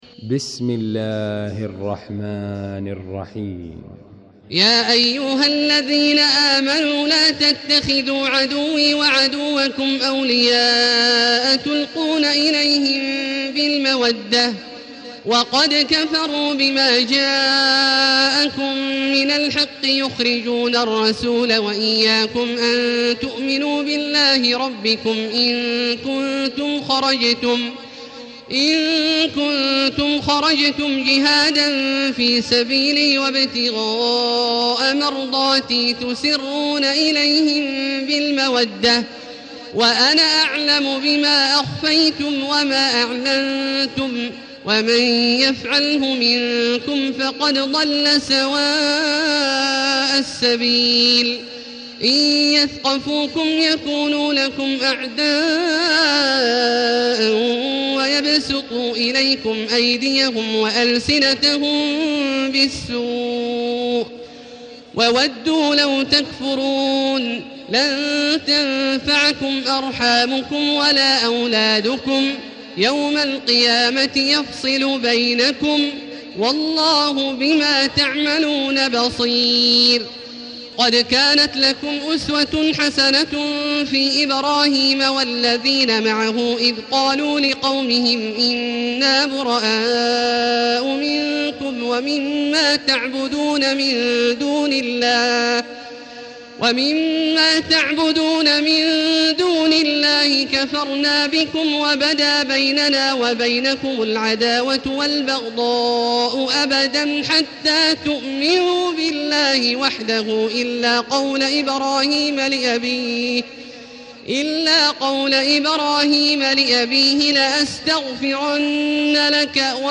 المكان: المسجد الحرام الشيخ: فضيلة الشيخ عبدالله الجهني فضيلة الشيخ عبدالله الجهني الممتحنة